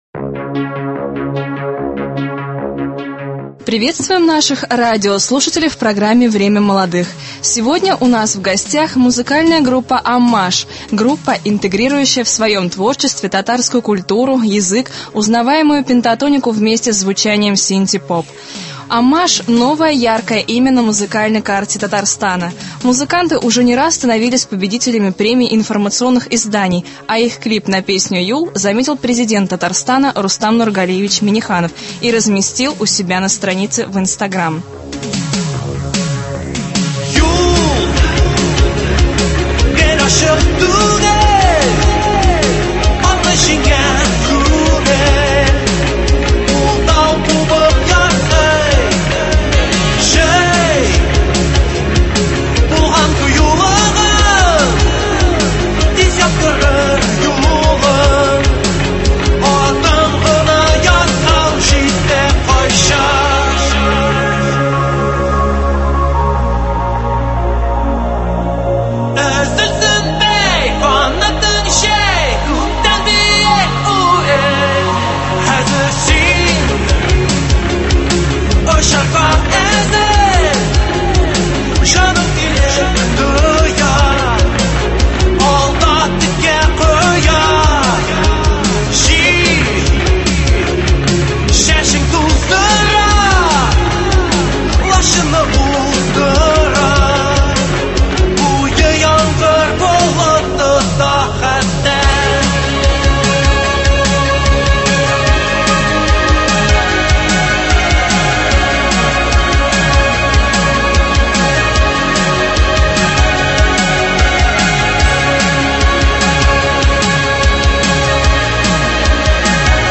В гостях — музыкальная группа «ОММАЖ». Беседа